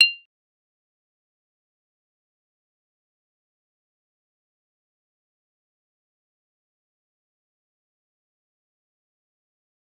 G_Kalimba-F8-mf.wav